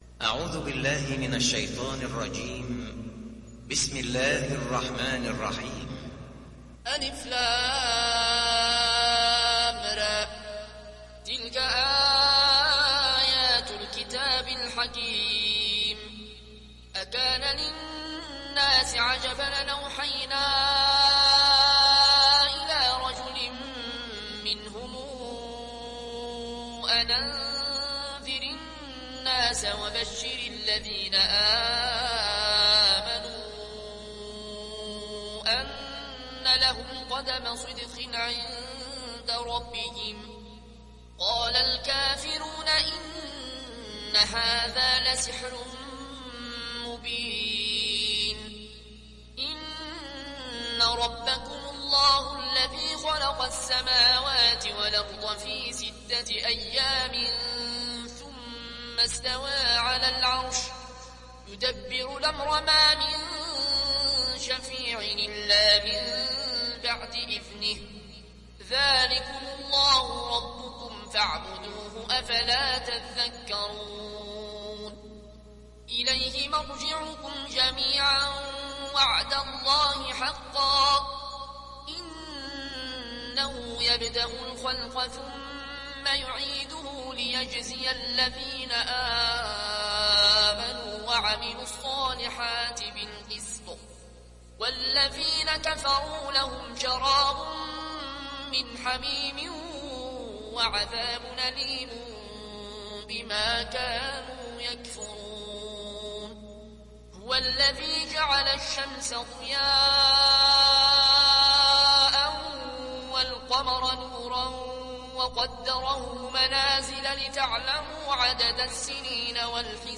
(رواية ورش)